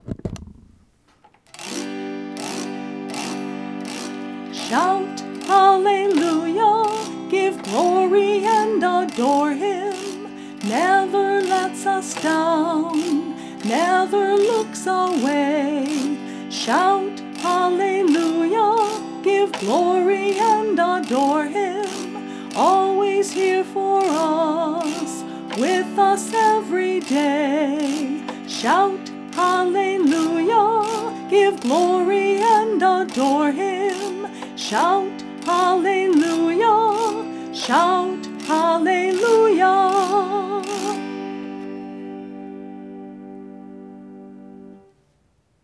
This entry was posted in Christian Music, Lent, Uncategorized and tagged , , , , , , , , .